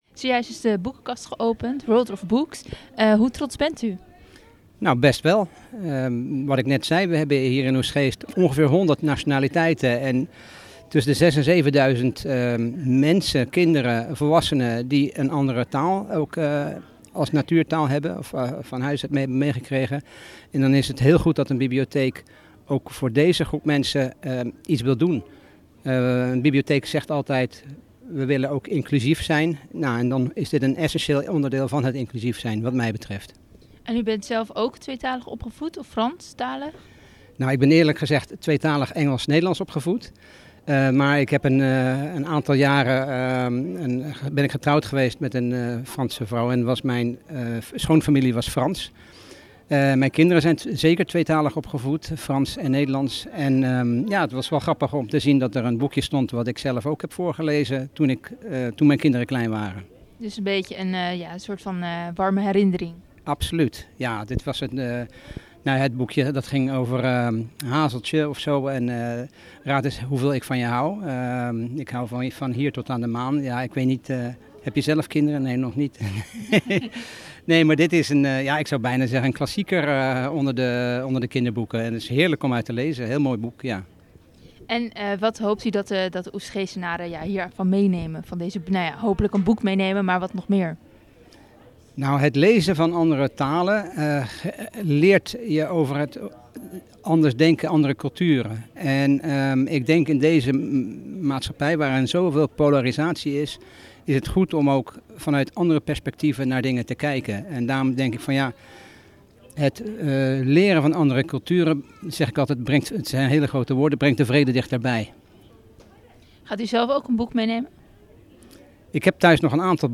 Wethouder Kees Oudendijk over het initiatief van de boekenkast.